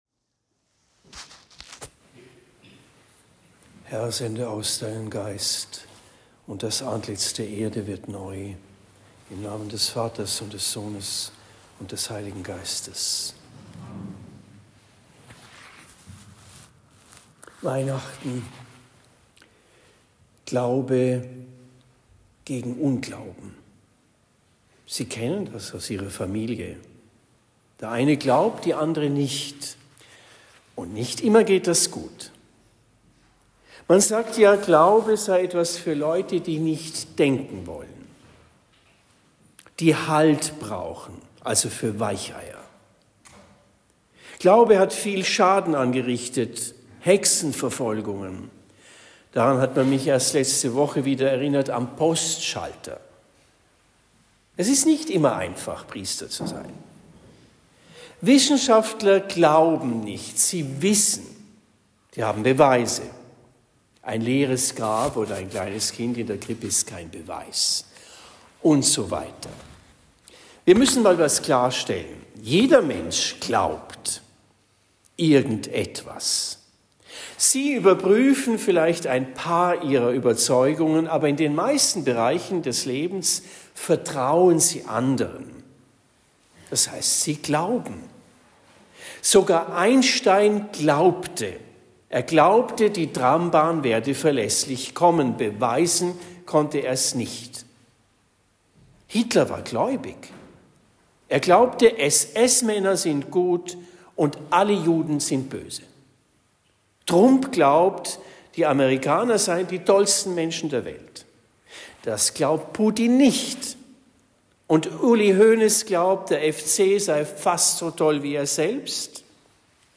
Predigt am 24. Dezember 2024 in Marienbrunn